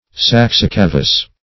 Search Result for " saxicavous" : The Collaborative International Dictionary of English v.0.48: Saxicavous \Sax`i*ca"vous\, a. [L. saxum rock + cavare to make hollow, fr. cavus hollow: cf. F. saxicave.]